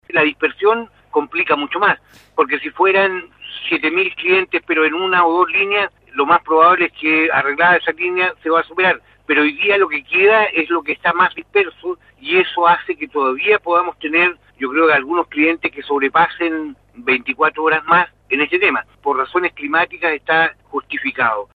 En entrevista con Radio Bío Bío, el seremi de Energía, Erwin Gudenschwager, confirmó que son cerca de 7 mil personas sin energía eléctrica en la región, quienes podrían mantenerse en la misma situación por al menos 24 horas más.